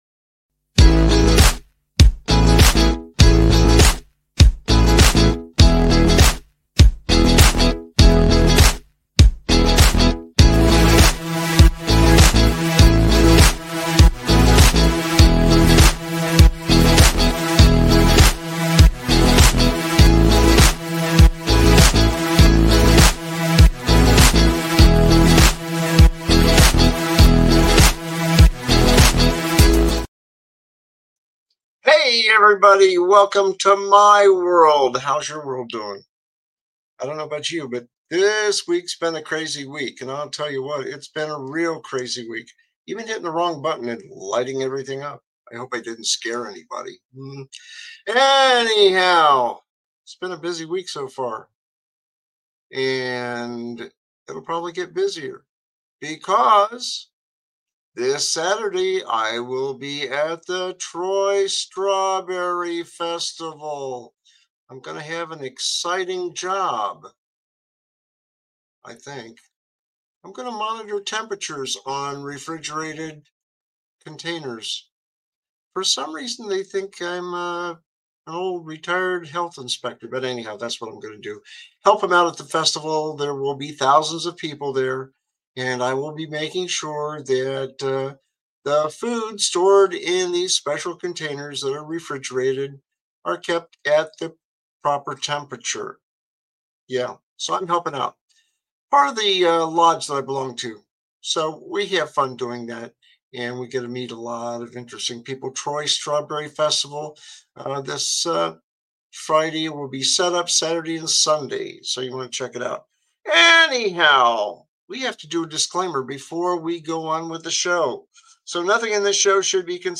Expect plenty of laughter, thought-provoking discussions, and honest conversations.